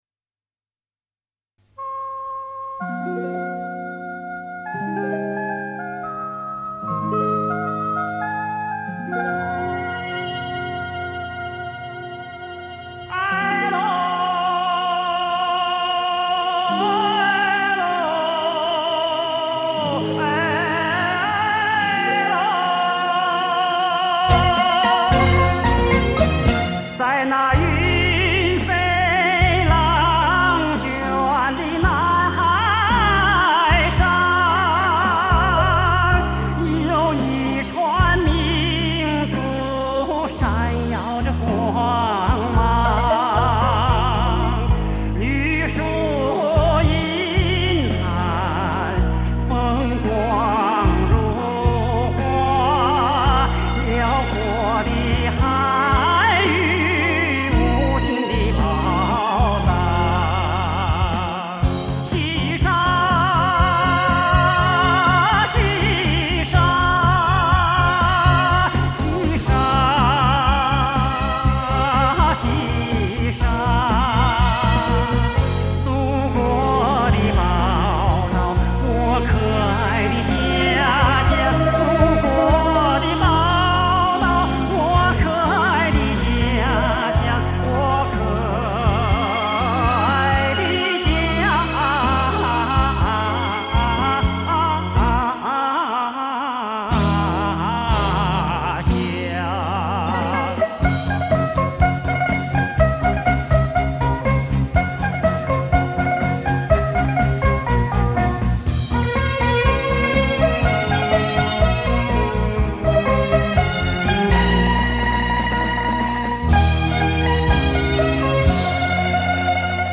声音高亢 演唱流畅 行腔富于中国传统韵味